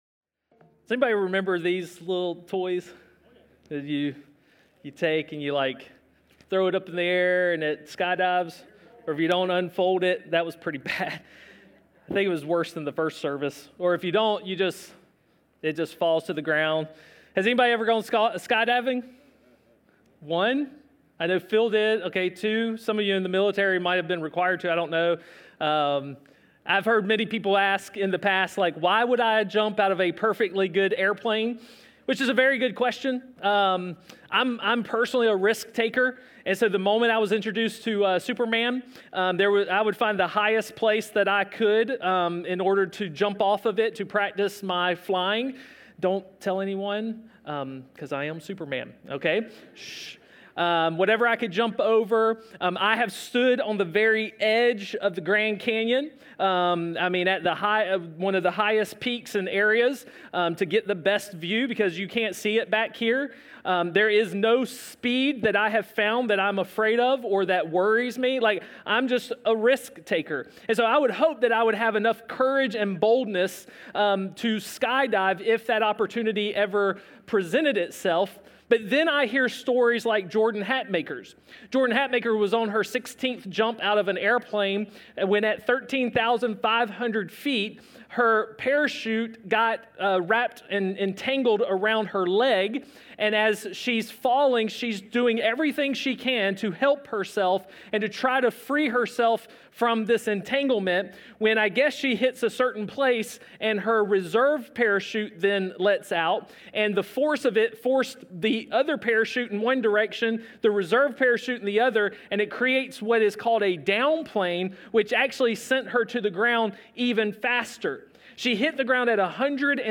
A message from the series "Love Awakened."